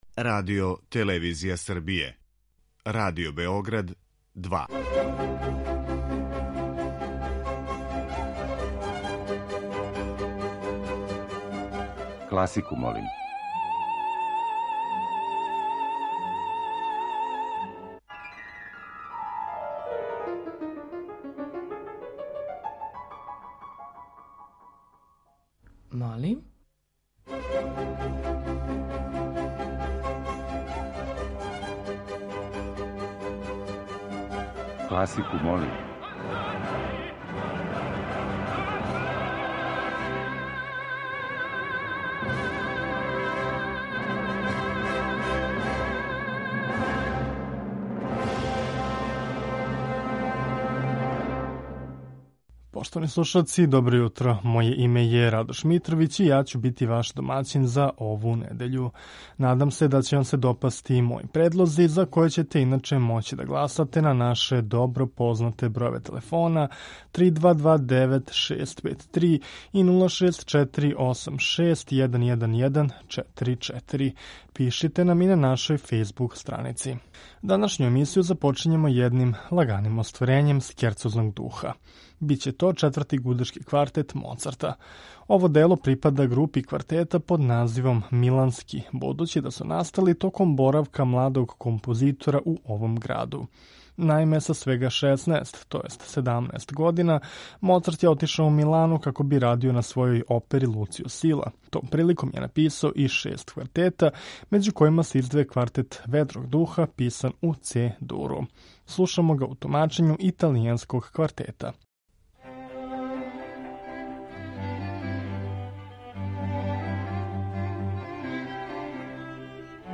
У емисији Класику, молим ове недеље окосница ће нам бити увертире познатих или мање познатих оперских остварења.